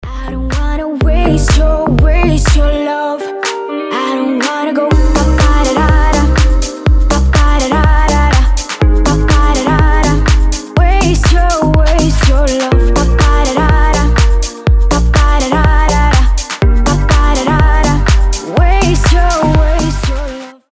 женский голос
Dance Pop
легкие